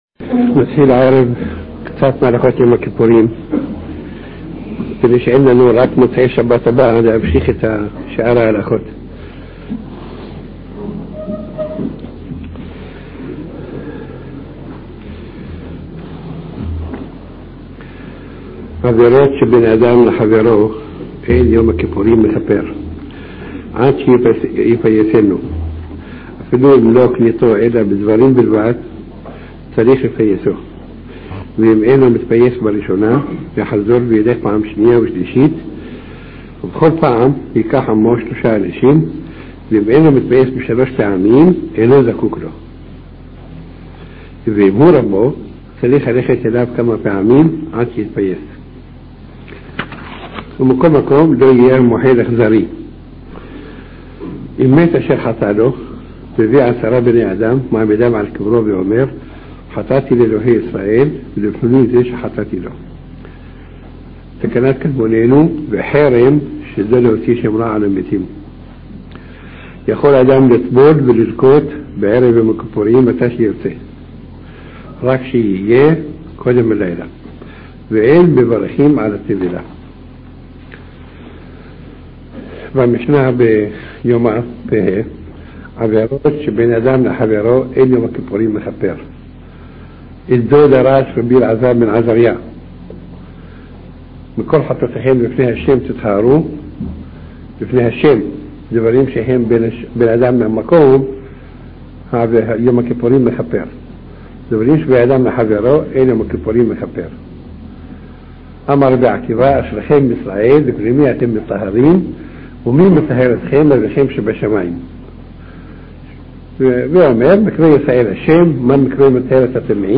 שיעור מיוחד מאת מרן רבינו עובדיה יוסף זיע״א, העוסק בהלכות יום כיפור ובפרט בדיני מחילה וכפרה בין אדם לחבירו – הדרכה מעשית כיצד להיטהר ולהתכונן כראוי ליום הקדוש.